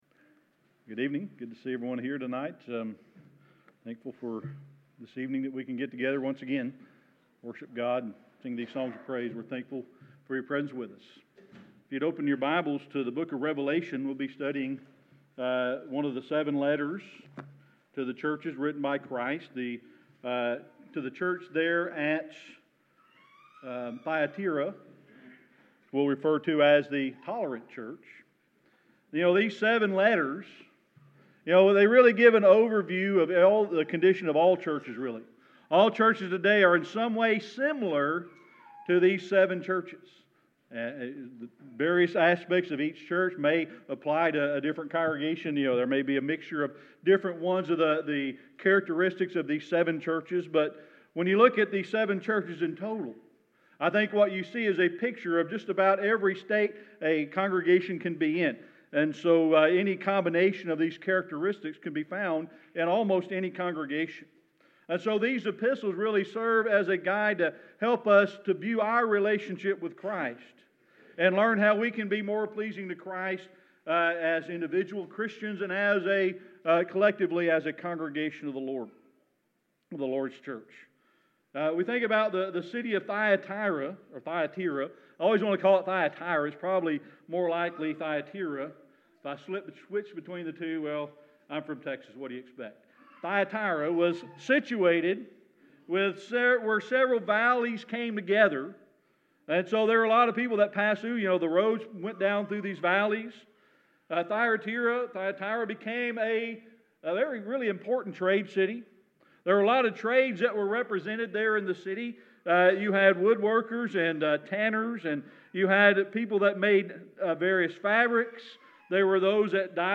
Sermon Archives
Service Type: Sunday Evening Worship